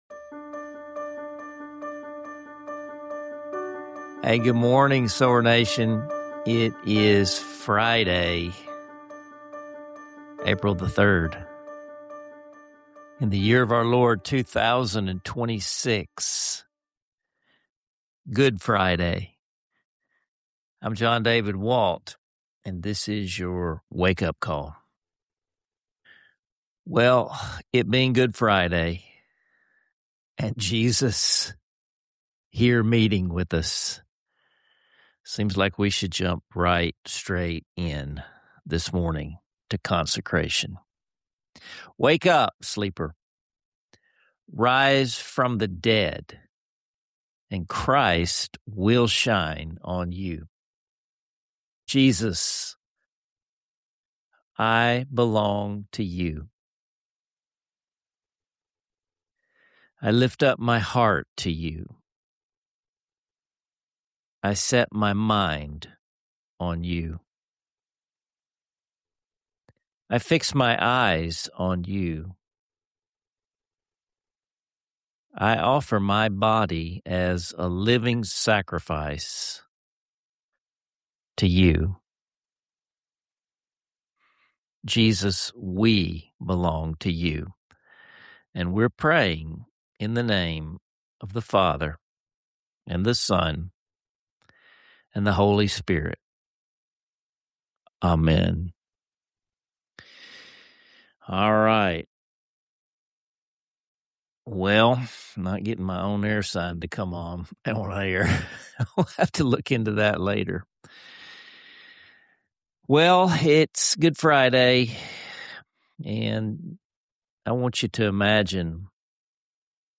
With warm stories, thought-provoking questions, and a heartfelt musical moment, this conversation invites you to slow down, breathe deep, and uncover the heart of what it means to belong to Jesus.